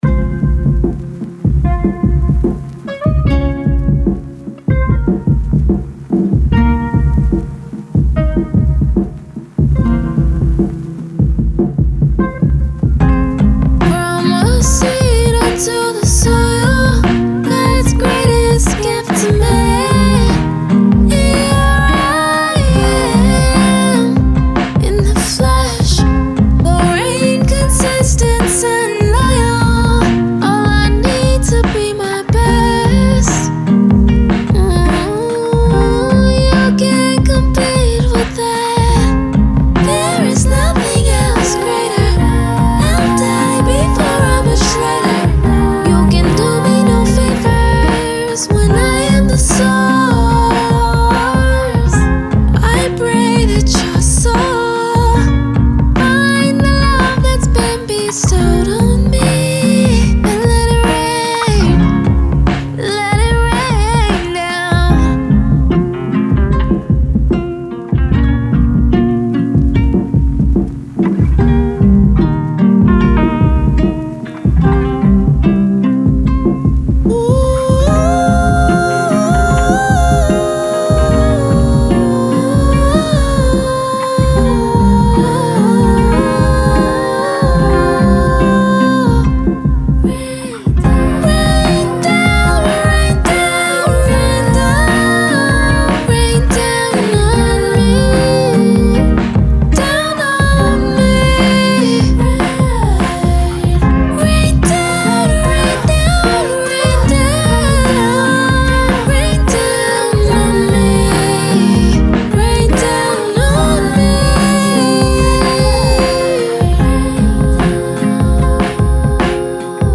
R&B, Alternative R&B
C min